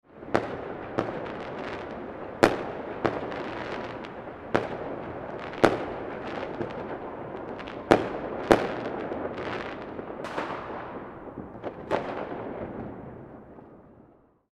Street-fireworks-sound-effect.mp3